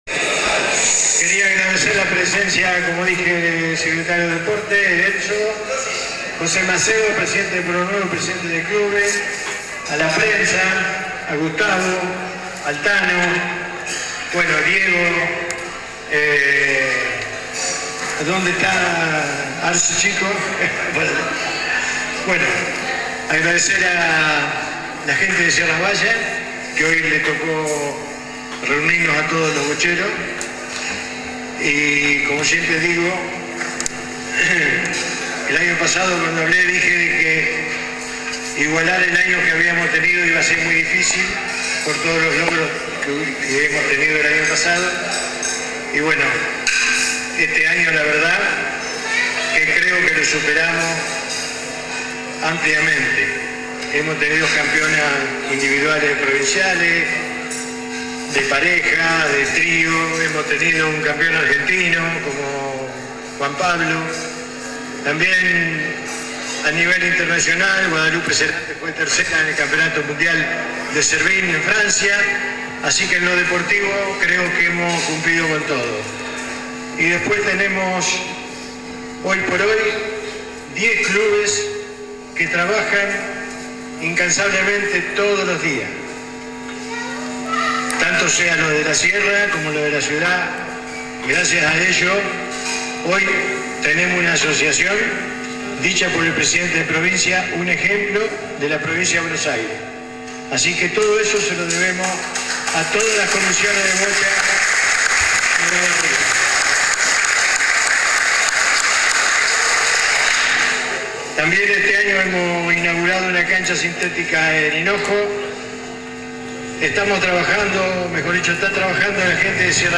Fue durante el transcurso de una cena en el Centro Cultural de Sierras Bayas.